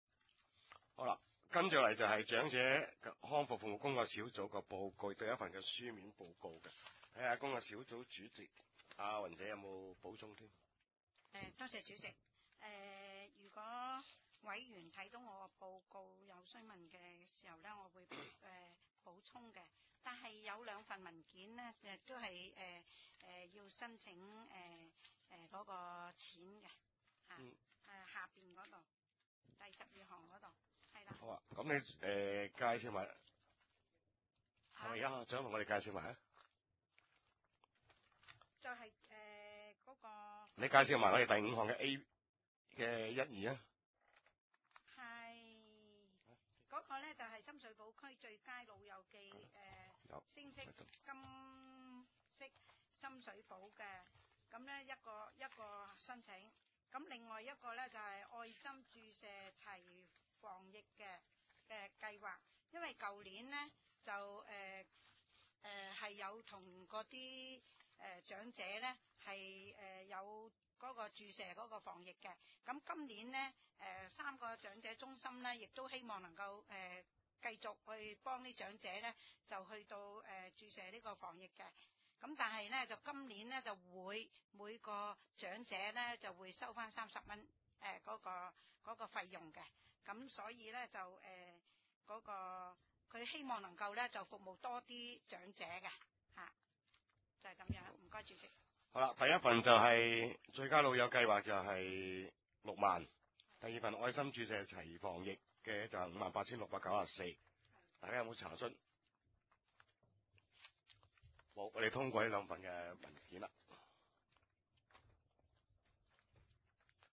地點：深水埗區議會會議室